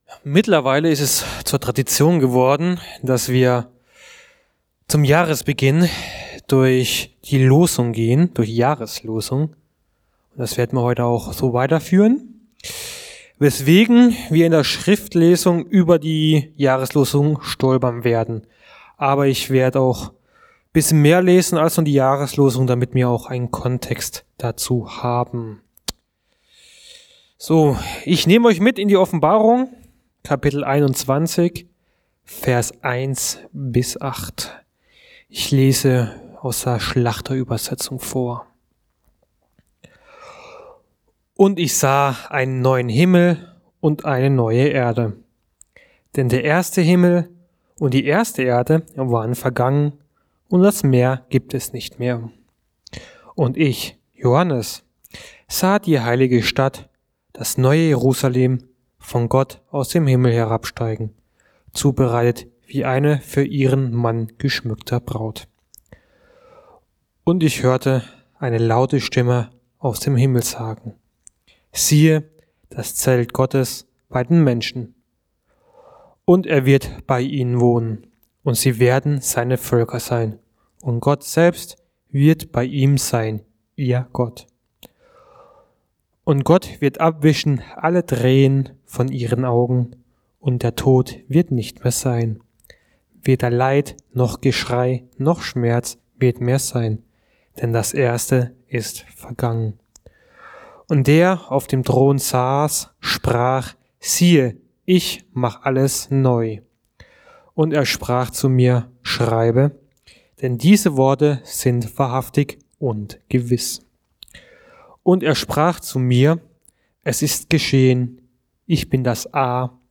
Gott Macht Alles Neu ~ Mittwochsgottesdienst Podcast